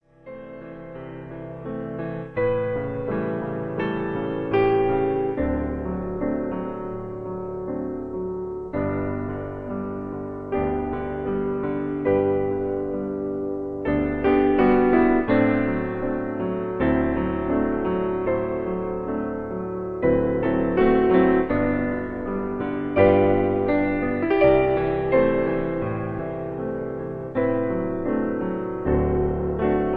Das bekannte Gutenachtlied aus Deutschland.